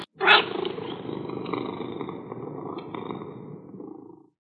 PixelPerfectionCE/assets/minecraft/sounds/mob/cat/purreow2.ogg at mc116
purreow2.ogg